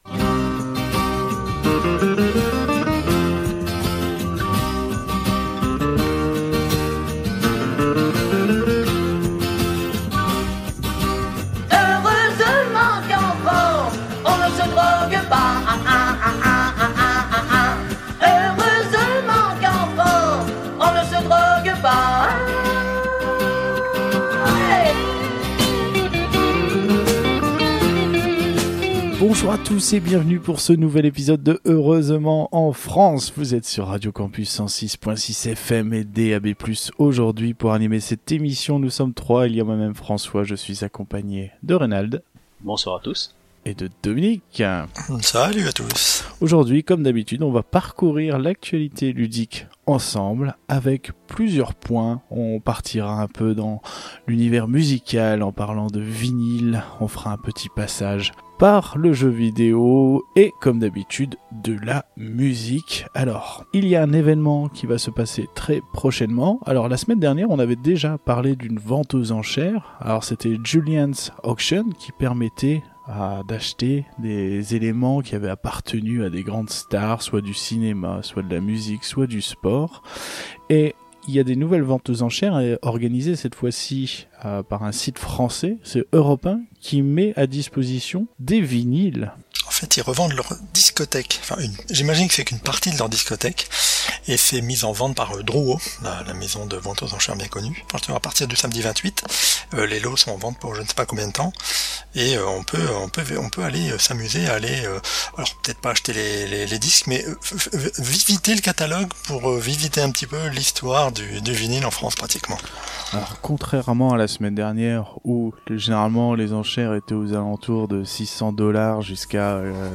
Au sommaire de cet épisode diffusé le 29 novembre 2020 sur Radio Campus 106.6 :